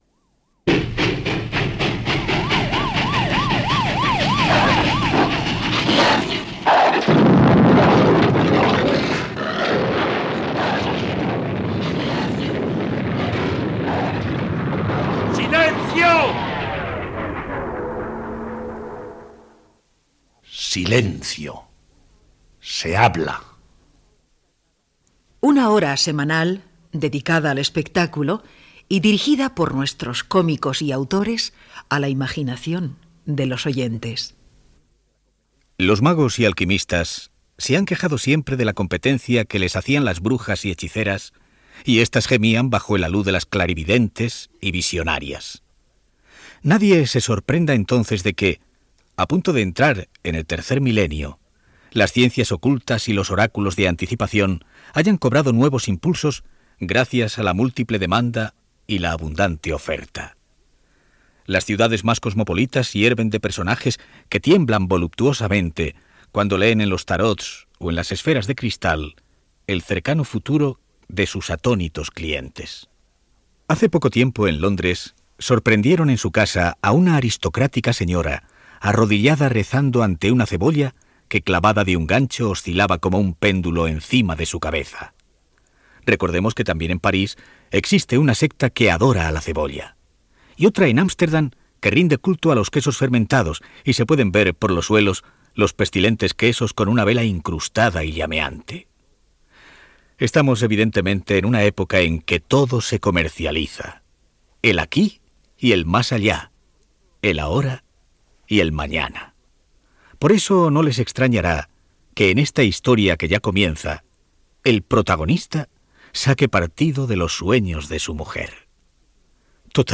Careta del programa. Ficció sonora "Oráculos de muerte"